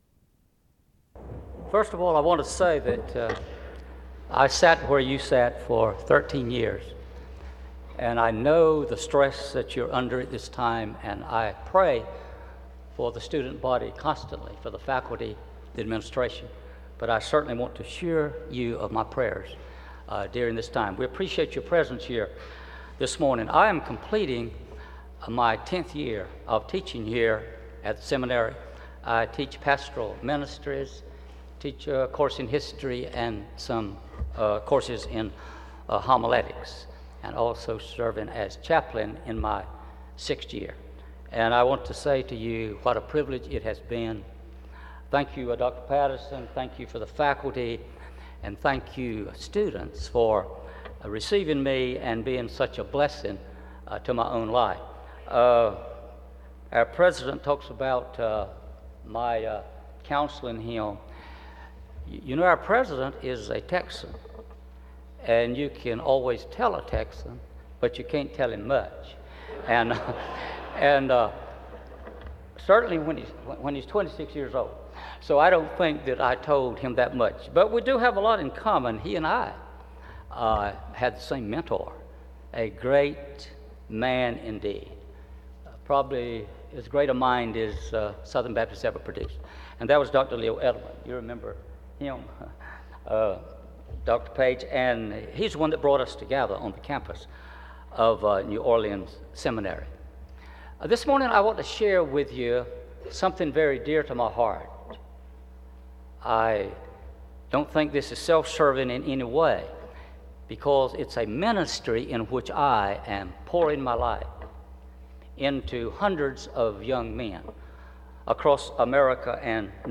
SEBTS Chapel and Special Event Recordings - 2000s